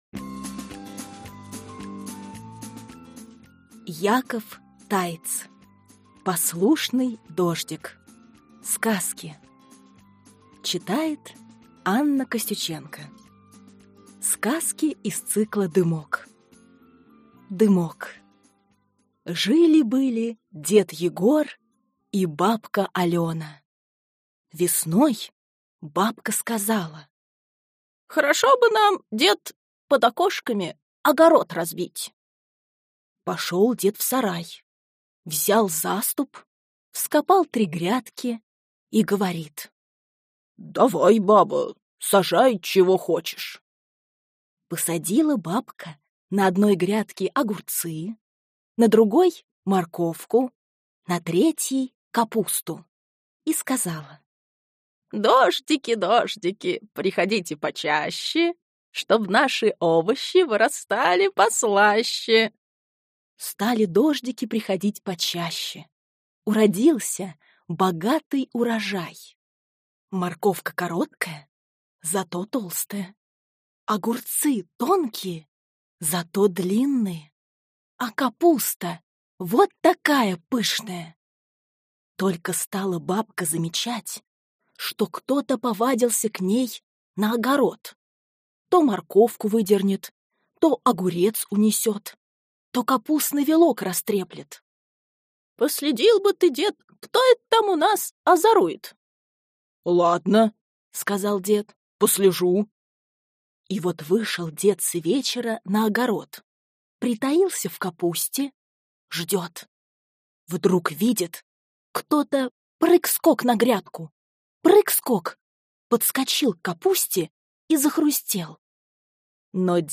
Аудиокнига Послушный дождик | Библиотека аудиокниг